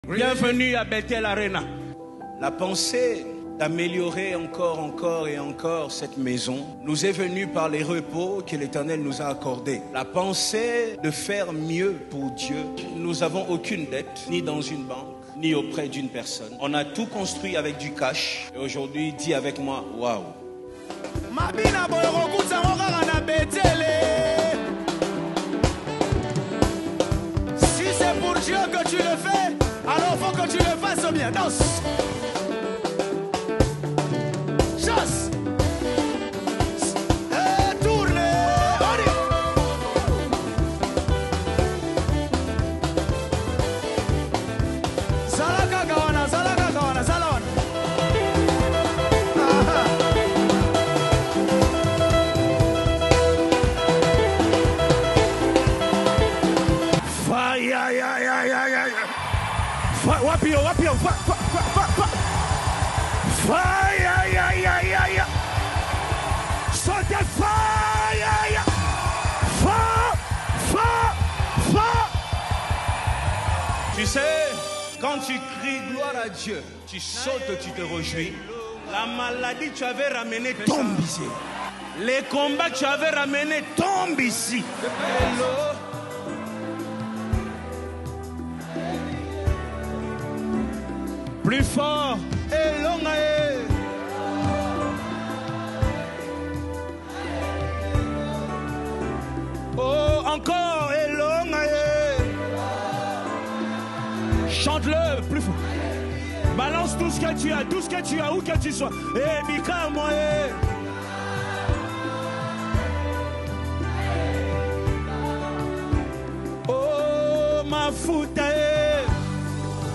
Congo Gospel Music
a powerful worship anthem